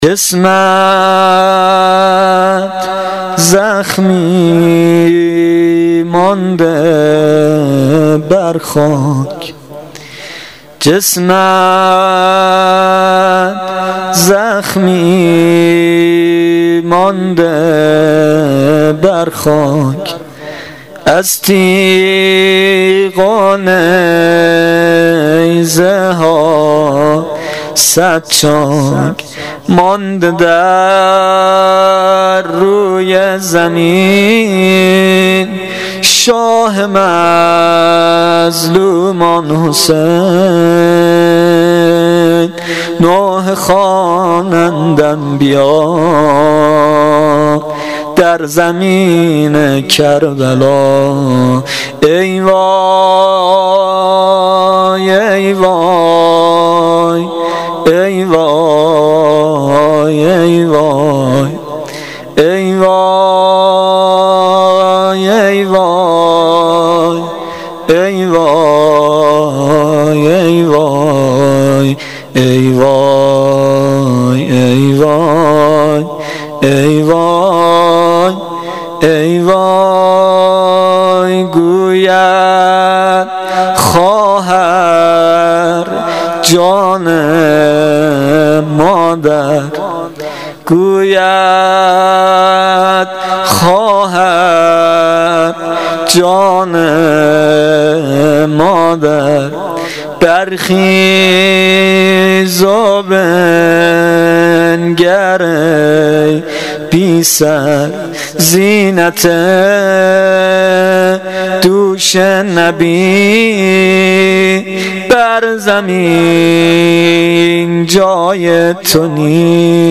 شب پنجم محرم, زمزمه, روضه